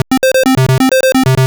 retro_synth_beeps_groove_05.wav